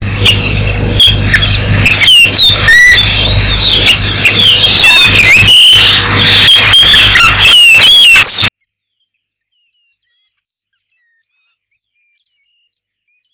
Agelaioides (Molothrus) badius - Músico
musico.wav